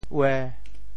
潮州发音 潮州 uê1